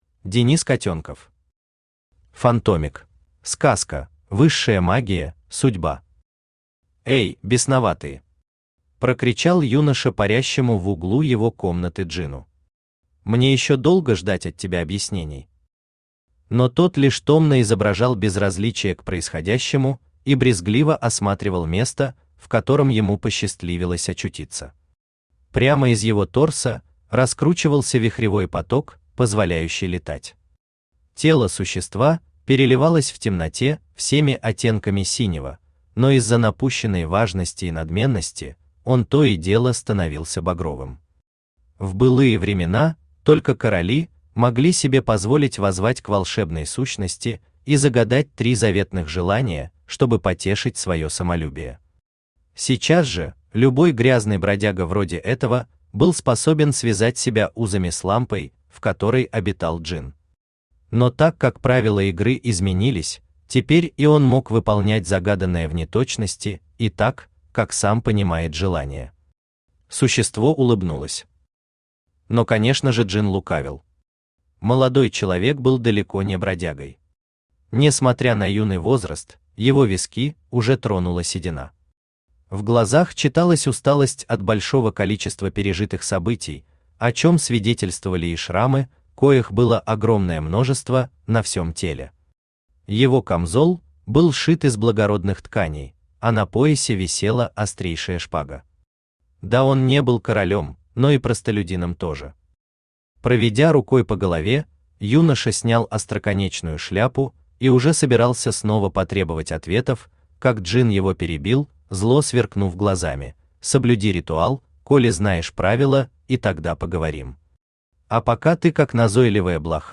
Аудиокнига Фантомик | Библиотека аудиокниг
Aудиокнига Фантомик Автор Денис Владимирович Котенков Читает аудиокнигу Авточтец ЛитРес.